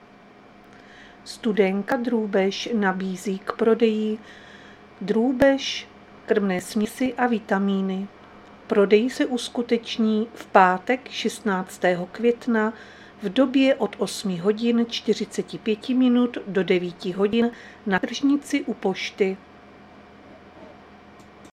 Záznam hlášení místního rozhlasu 13.5.2025